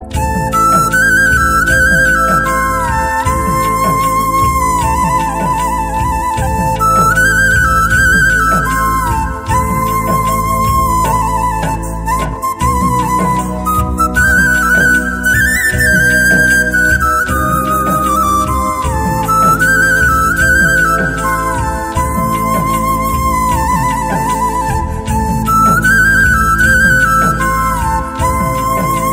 CategoryInstrumental / Flute
• 🎵 Relaxing Sound – Soft flute music reduces stress.